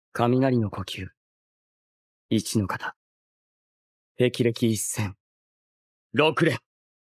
zenitsu lightning breath Meme Sound Effect
This sound is perfect for adding humor, surprise, or dramatic timing to your content.
zenitsu lightning breath.mp3